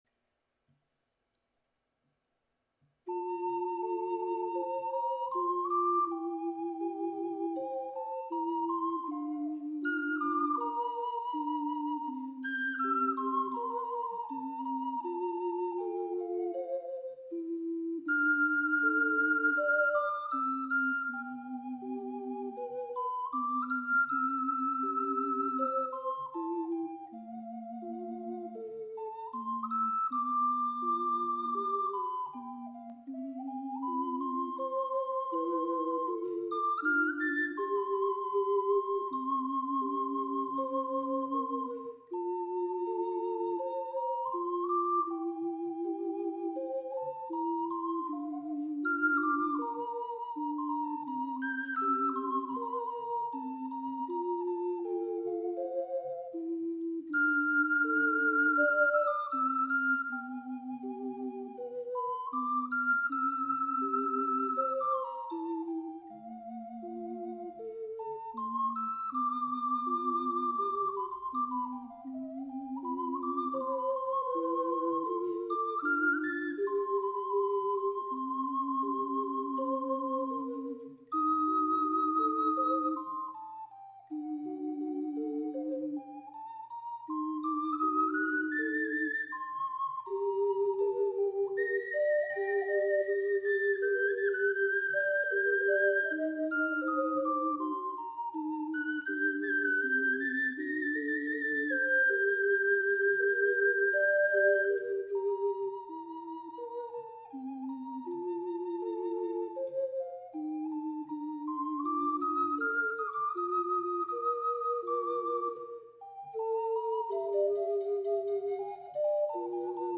三重奏
3rdの最後の音は2小節＋フェルマータ分を伸ばすことになっていますが、私は息が続かないので、1小節ずつに分けました。